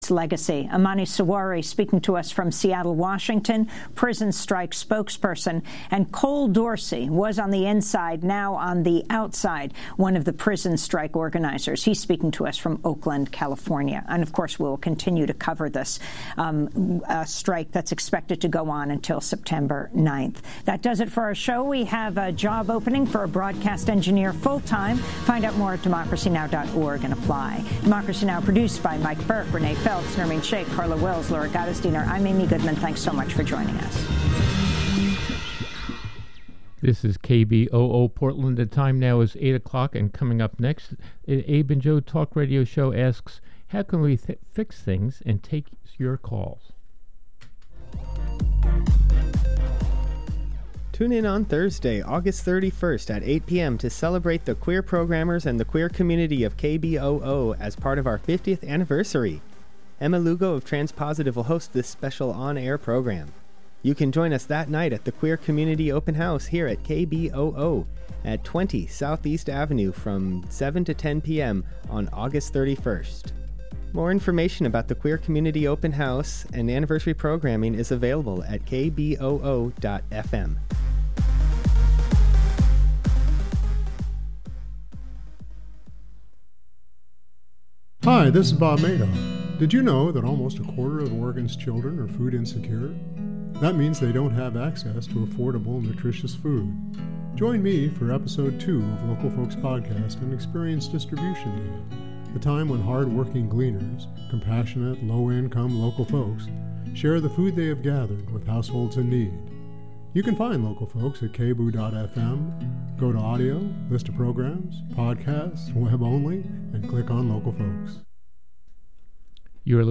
Talk Radio Show